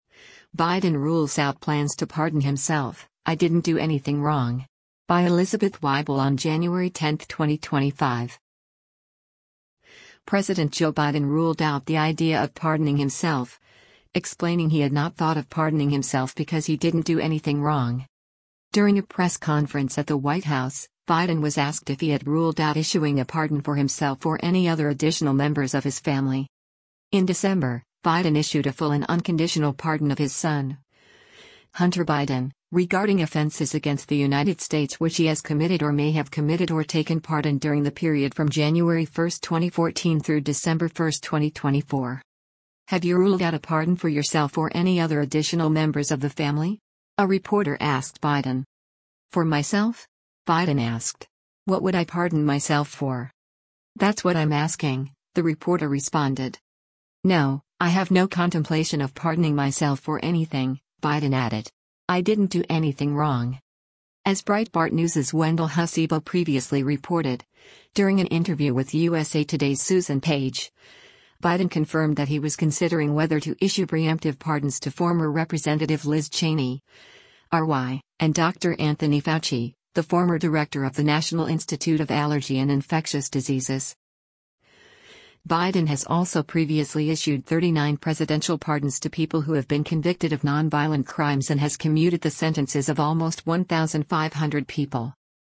During a press conference at the White House, Biden was asked if he had “ruled out” issuing a pardon for himself or “any other additional members” of his family.
“Have you ruled out a pardon for yourself or any other additional members of the family?” a reporter asked Biden.